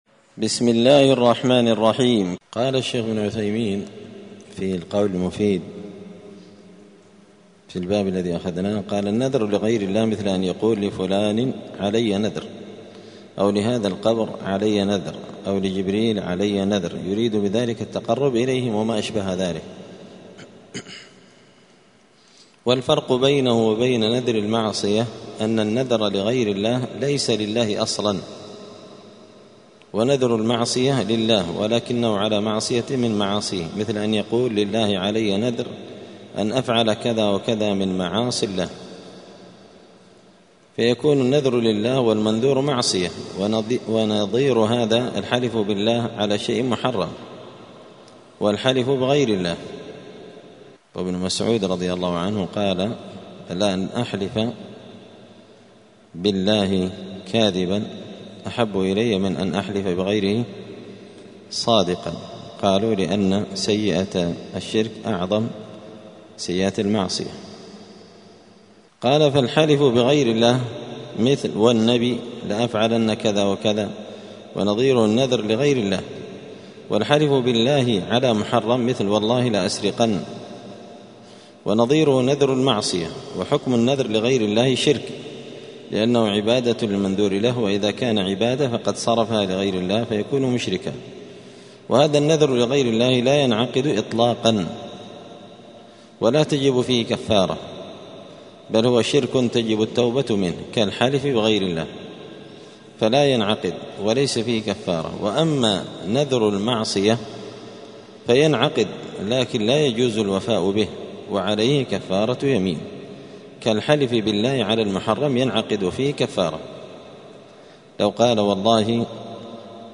دار الحديث السلفية بمسجد الفرقان بقشن المهرة اليمن
*الدرس الحادي والأربعون (41) {الباب الثالث عشر باب من الشرك الاستعاذة بغير الله…}*